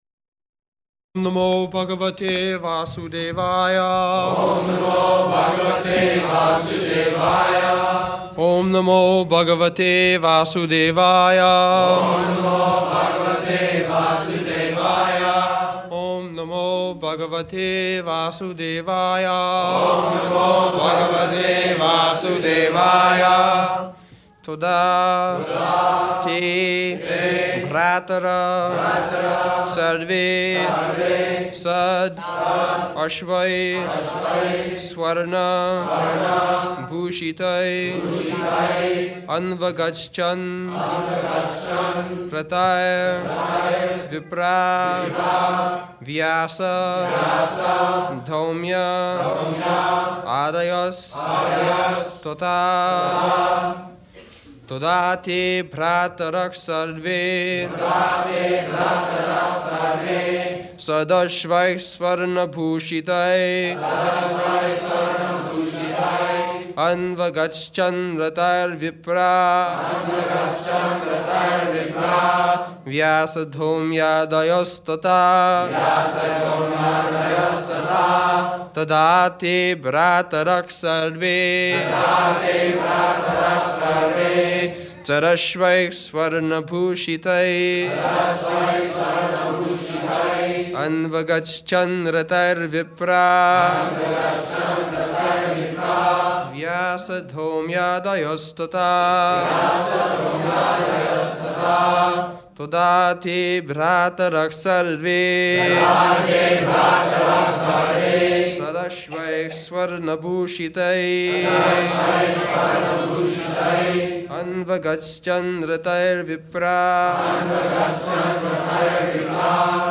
May 16th 1973 Location: Los Angeles Audio file
[leads chanting of verse] [Prabhupāda and devotees repeat]
[laughter] Where is that gold?